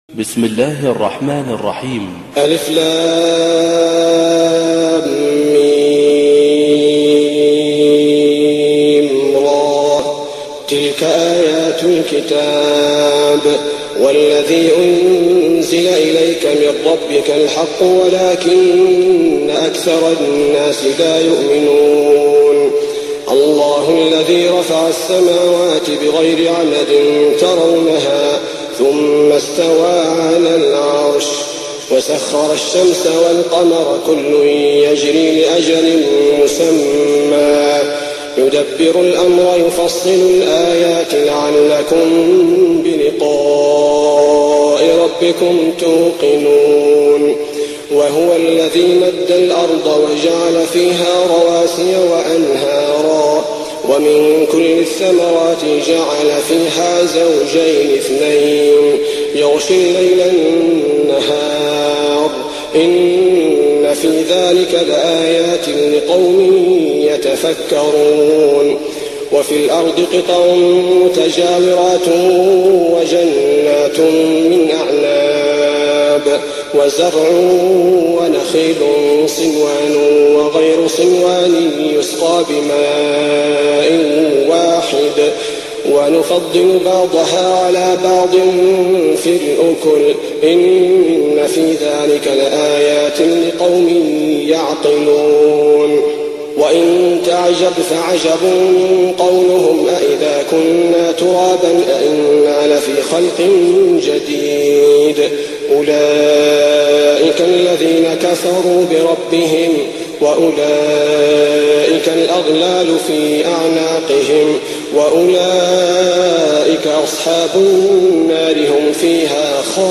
تهجد رمضان 1419هـ من سورة الرعد (1-18) Tahajjud Ramadan 1419H from Surah Ar-Ra'd > تراويح الحرم النبوي عام 1419 🕌 > التراويح - تلاوات الحرمين